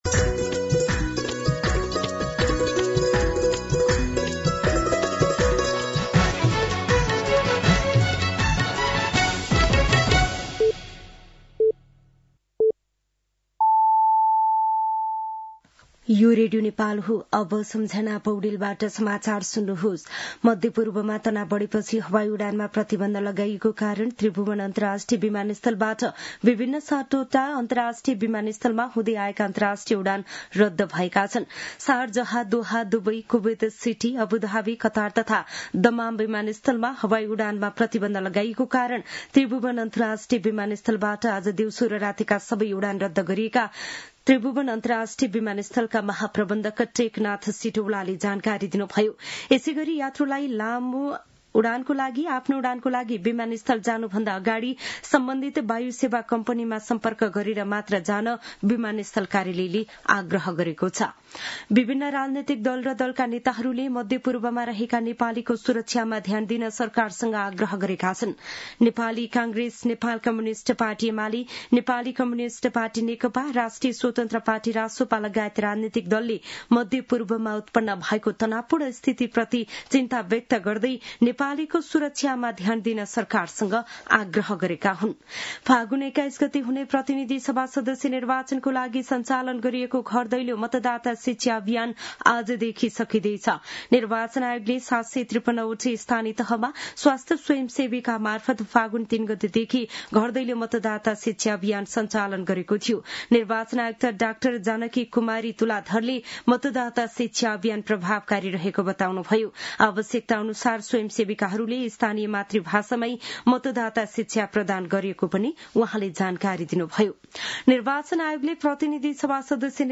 दिउँसो १ बजेको नेपाली समाचार : १७ फागुन , २०८२